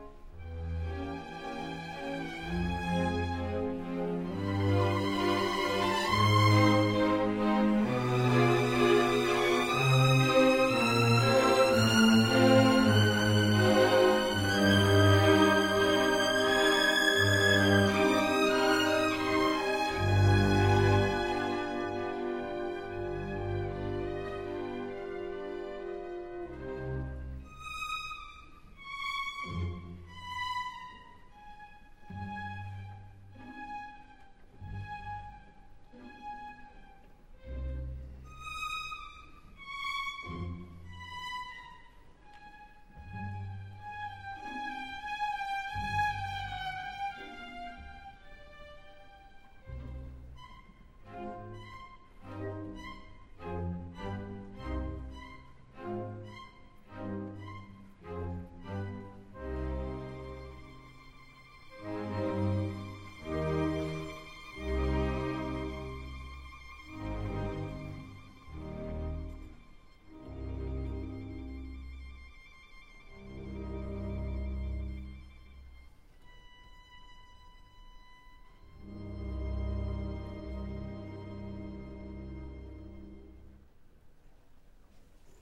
Eighth note = 94, 102 (Lots of Rubato)